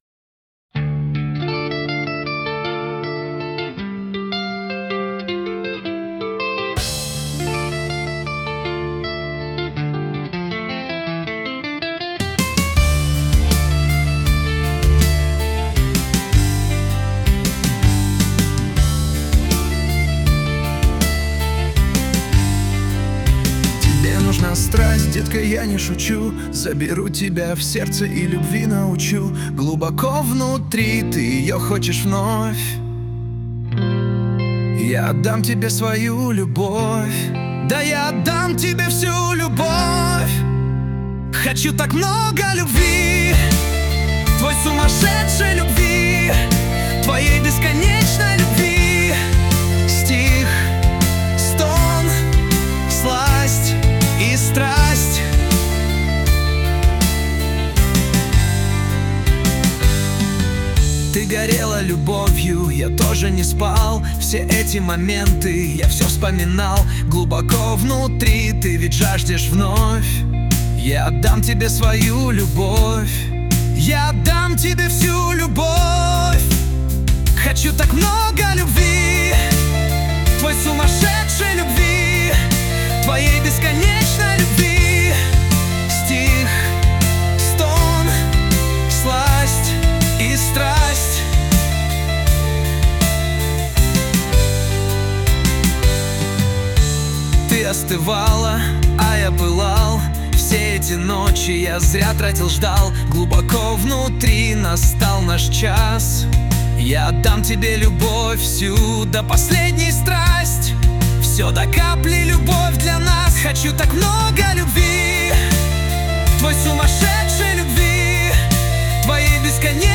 RUS, Romantic, Lyric, Rock, Indie | 03.04.2025 20:53